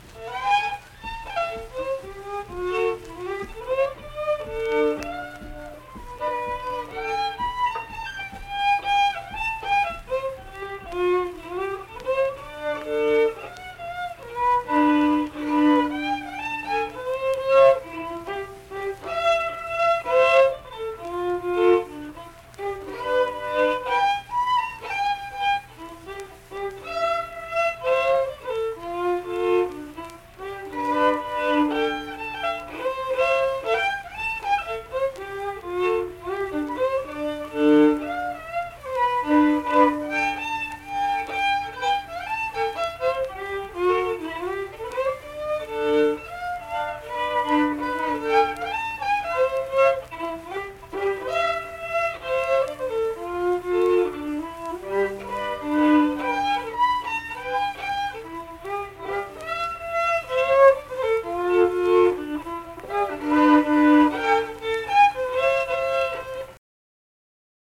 (Hornpipe)
Unaccompanied fiddle performance
Instrumental Music
Fiddle
Middlebourne (W. Va.), Tyler County (W. Va.)